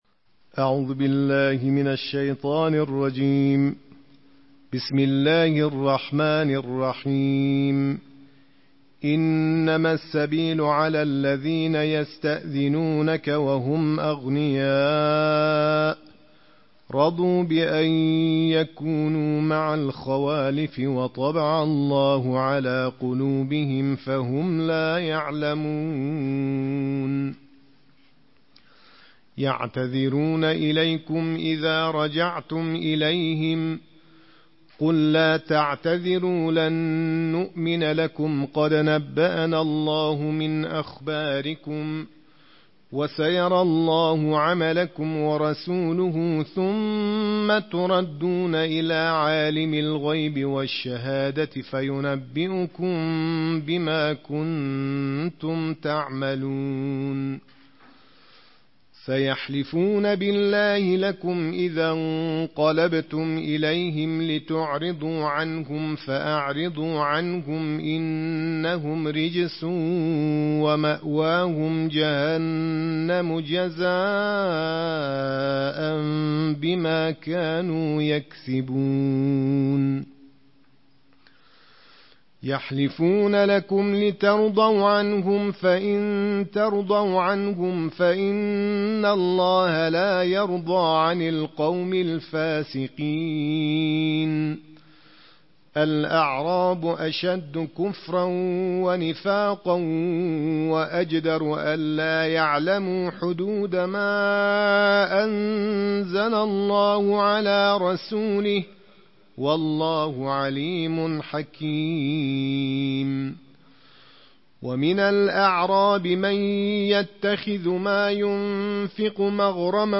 Daily Quran Recitation: Tarteel of Juz 11